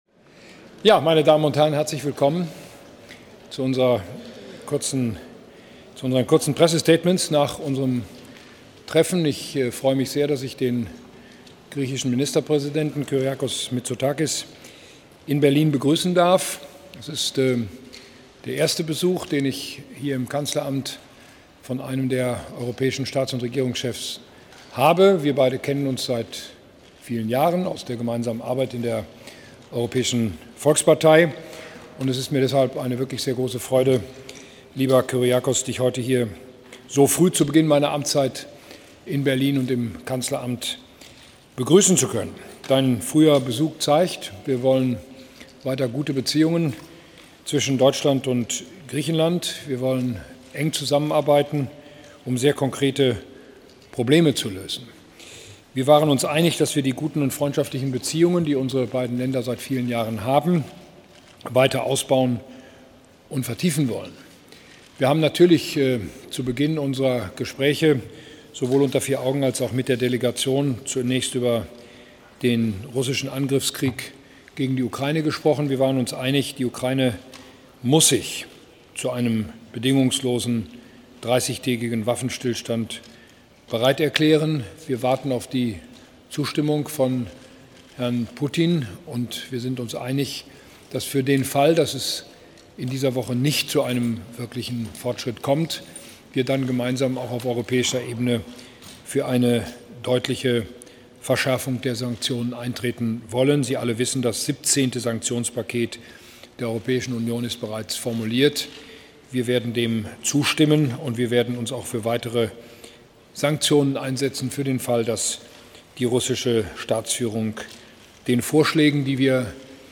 Video Pressekonferenz von Kanzler Merz und dem griechischen Ministerpräsidenten